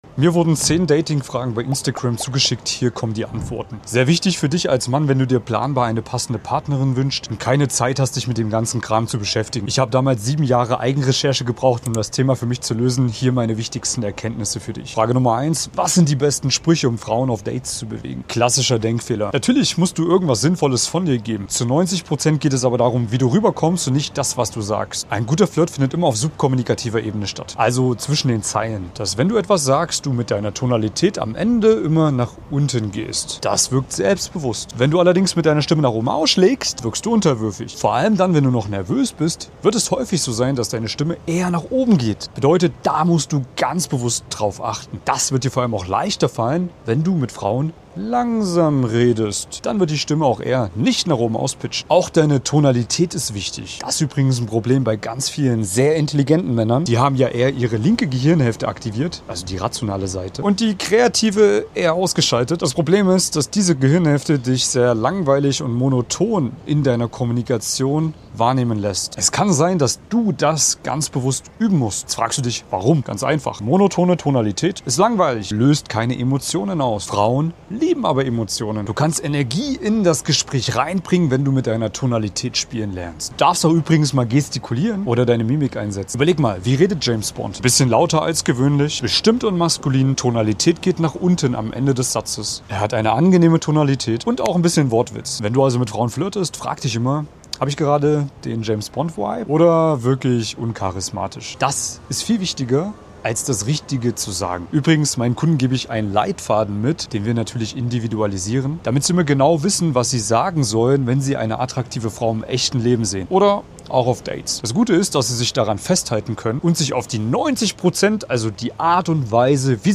Ich sitze in Málaga am Strand, spreche über meine Erfahrungen aus über 350 Coachings mit Männern über 30, und erkläre, woran es wirklich liegt, dass so viele erfolgr...